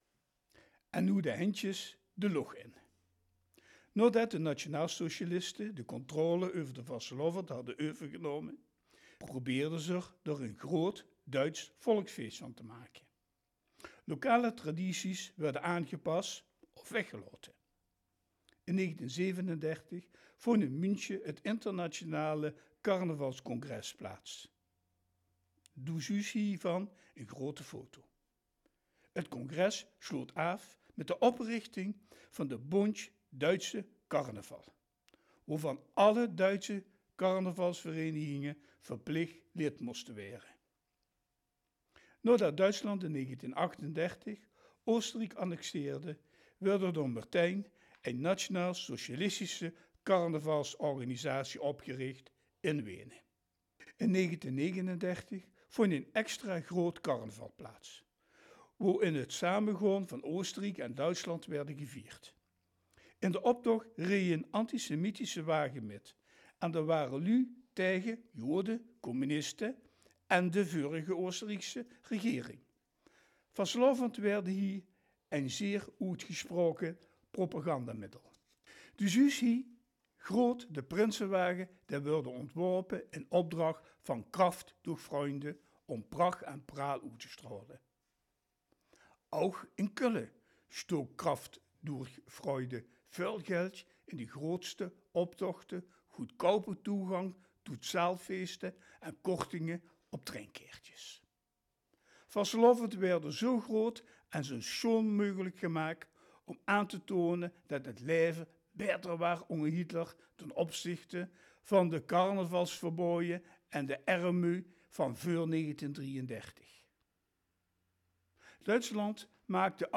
Carnaval audio-tour, deel 5
Luister in het Roermonds:
roermond-audiotour-5.m4a